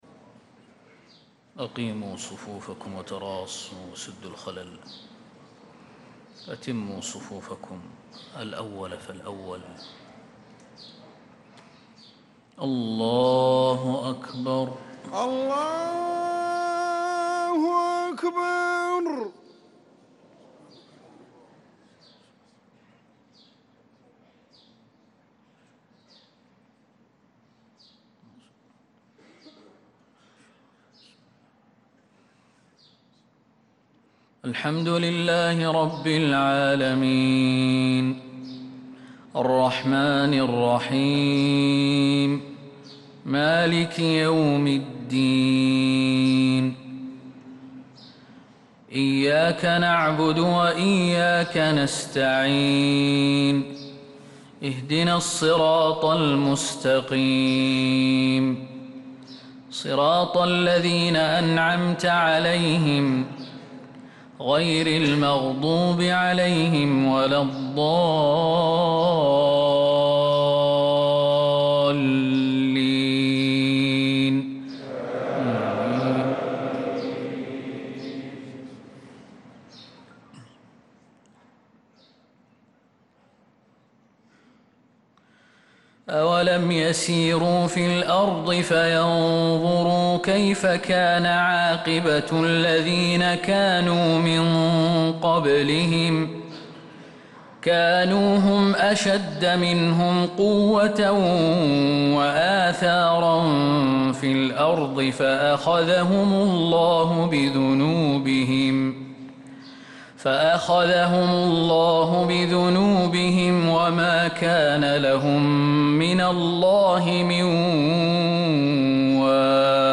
صلاة الفجر للقارئ خالد المهنا 20 ربيع الأول 1446 هـ